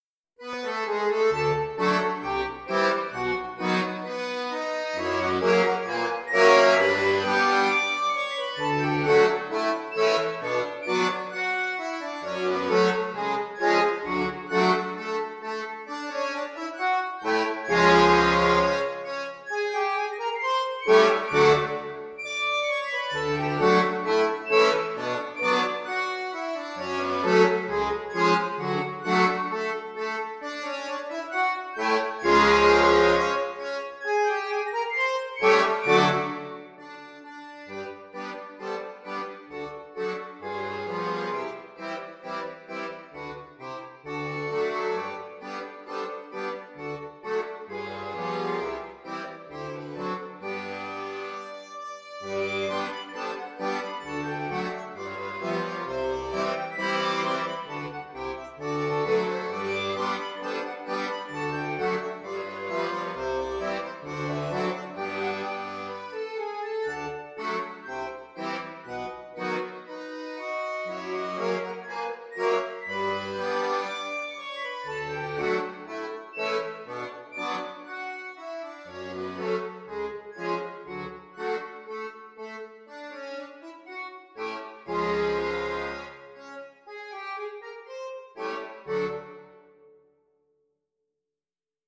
Diese fröhliche Gavotte
Klassisch